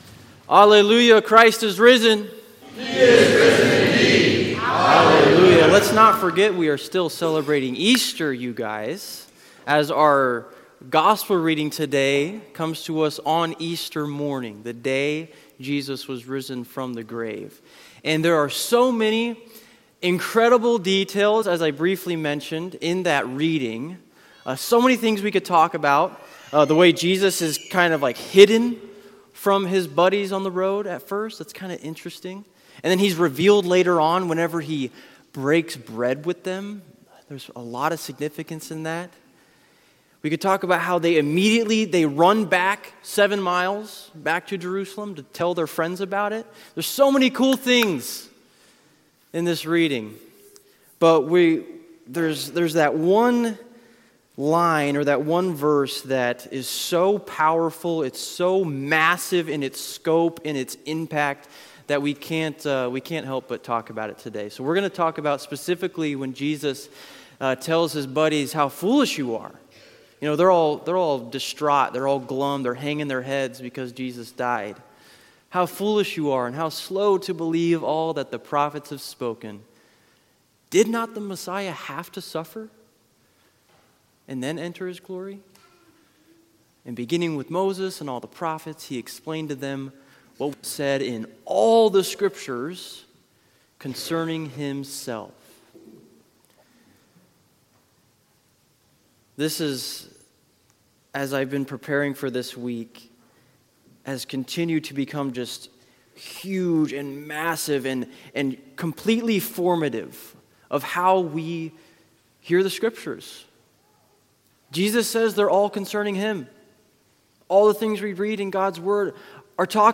We learn in Scripture that Jesus’ mission is to bring the Kingdom of Heaven down to us, and us to the Kingdom of Heaven. This sermon utilizes a BIBLE PROJECT video, which you can access HERE.